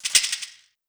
TS Perc 2.wav